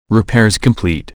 repaircomplete.wav